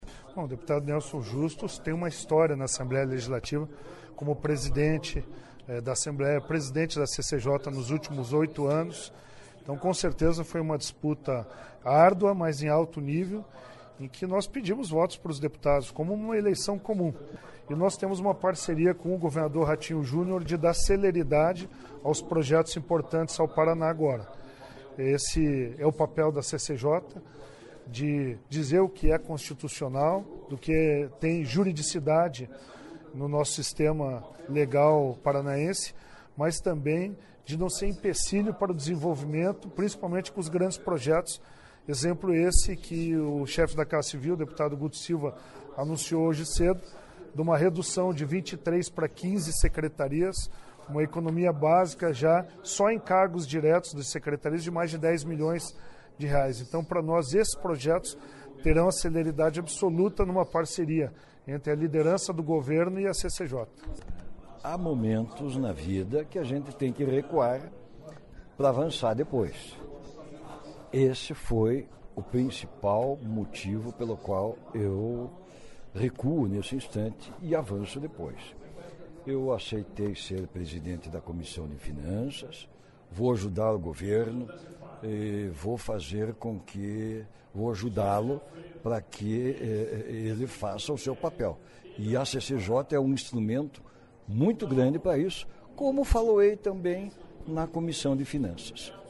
Francischini deve ser o novo presidente da CCJ, enquanto Justus é cotado para ssumir a Comissão de Finanças. Ouça as entrevistas .